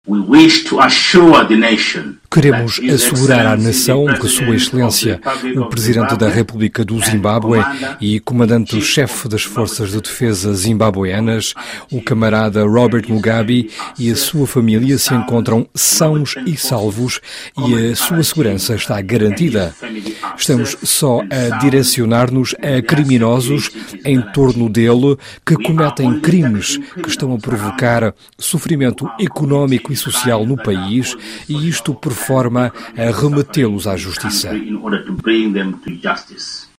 Alocução do general Sibusiso Moyo na televisão pública
ME_2_ZIMBABWE_Son_Manoeuvres_militaires.mp3